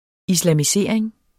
Udtale [ islaˈmiˈseˀɐ̯eŋ ]